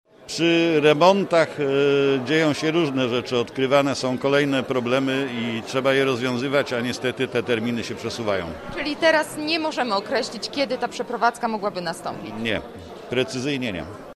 Mówi sekretarz miasta Eugeniusz Kurzawski: